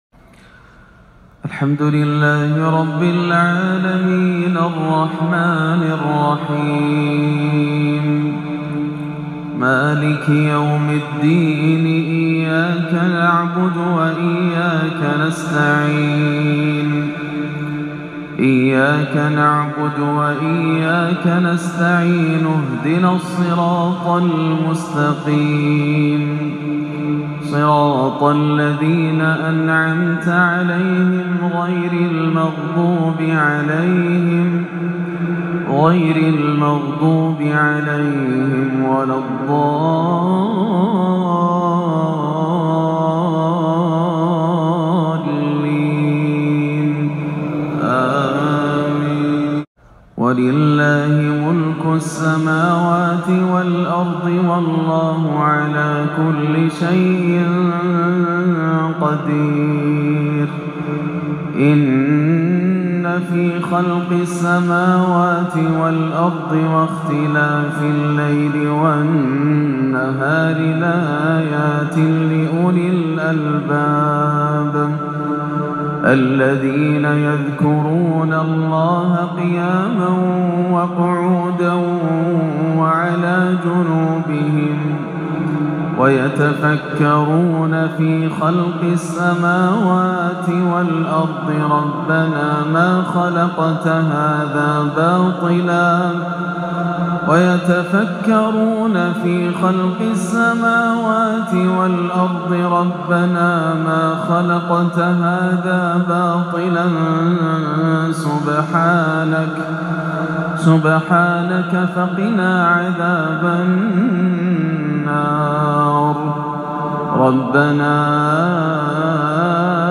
فجر الإربعاء 16 صفر 1438هـ من جامع الدخيل بحي الشهداء بمدينة الرياض لأواخر سورة آل عمران 189-200 و سورة الفجر > عام 1438 > الفروض - تلاوات ياسر الدوسري